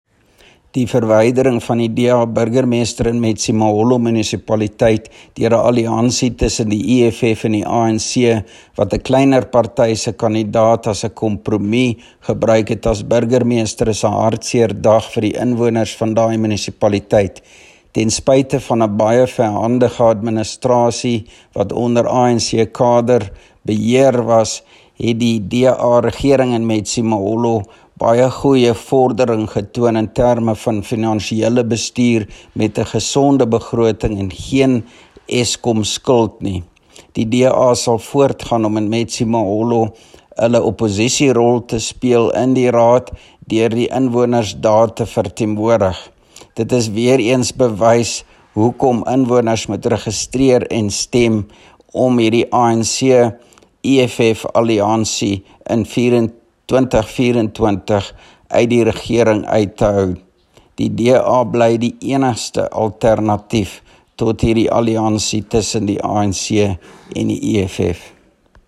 Afrikaans soundbites by Roy Jankielsohn MPL